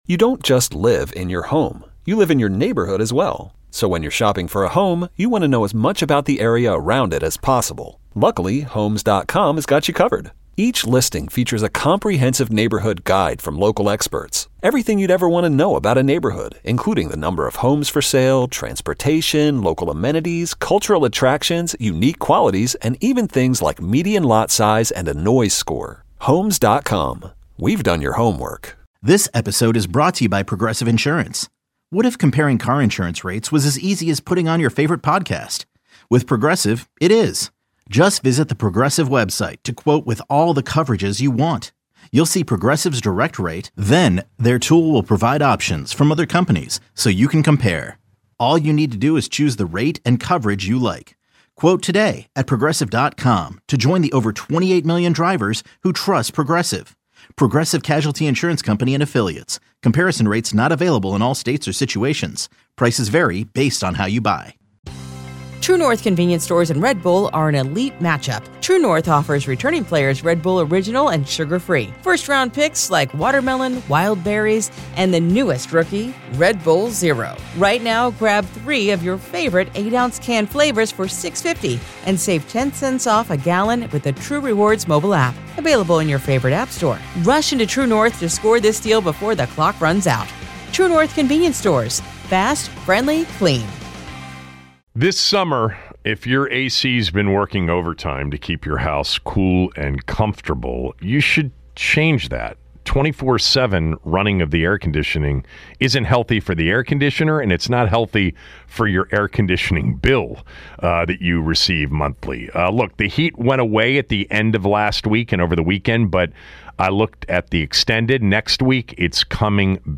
More calls on what fans think will happen with this Terry McLaurin contract negotiation saga.